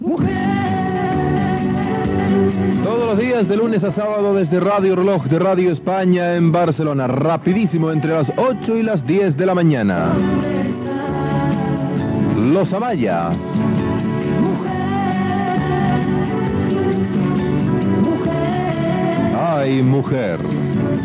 Comiat d'una cançó de Los Amaya
Musical